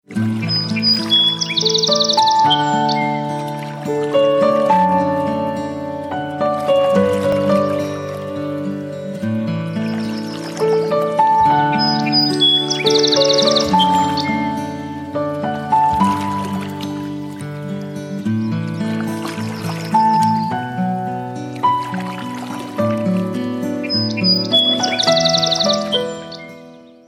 Спокойная мелодия для будильника